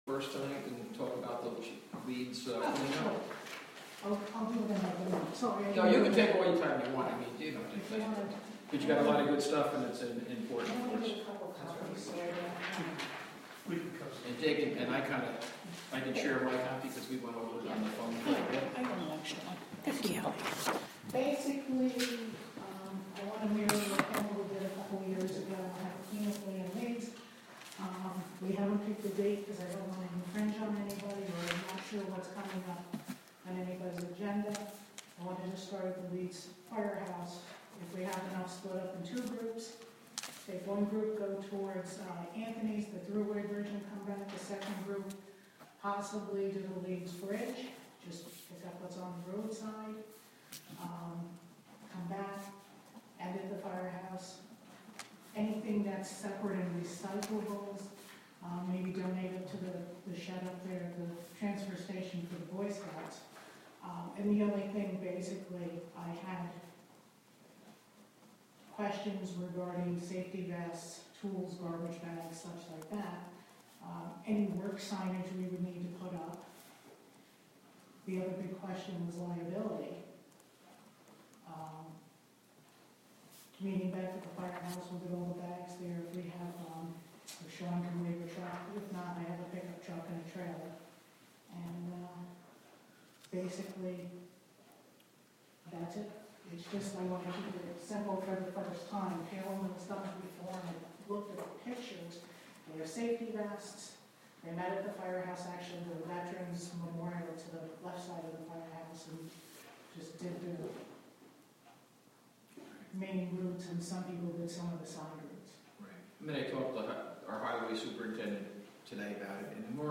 Live from the Town of Catskill: February Town Board Meeting (Audio)